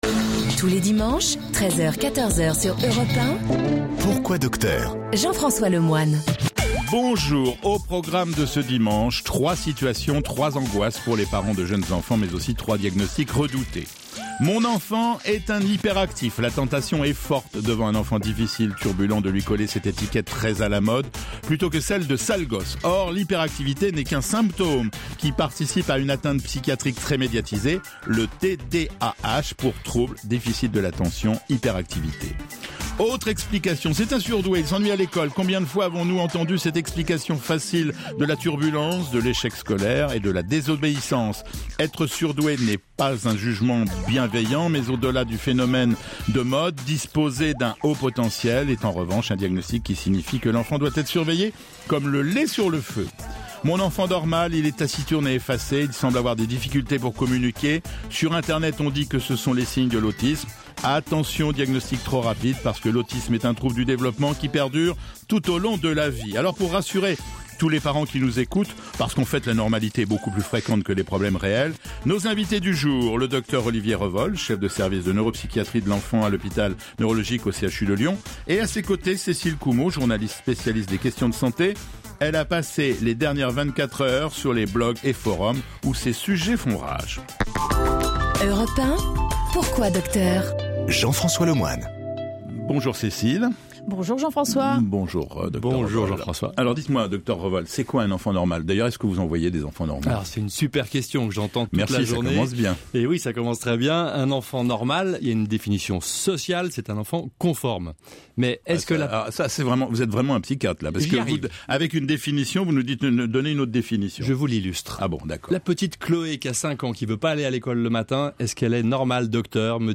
Dans « Pourquoi docteur ? », son émission dominicale, le docteur Jean-François Lemoine reçoit chaque semaine les meilleurs spécialistes pour les faire réagir aux questions des auditeurs et discuter des principales problématiques actuelles : les avancées de la médecine, les traitements novateurs, les médicaments retirés du marché…